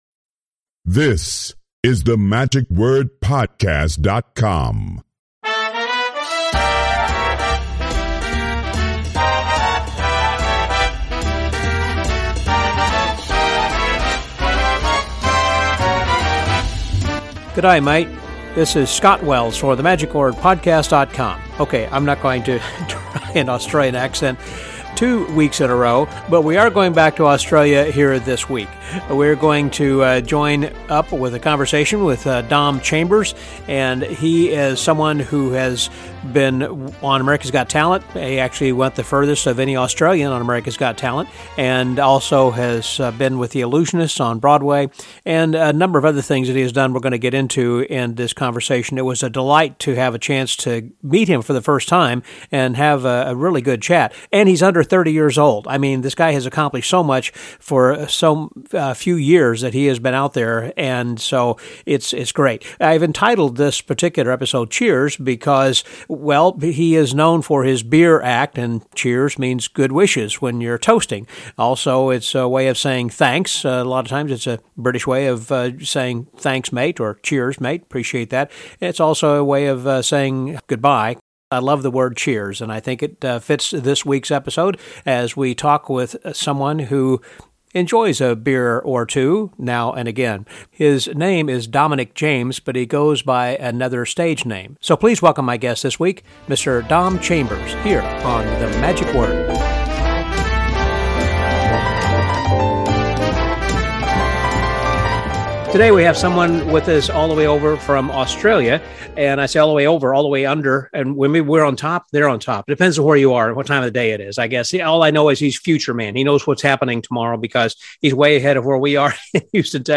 We continue our tour “down under” for a second consecutive week featuring a chat with Australian magician